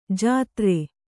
♪ jātre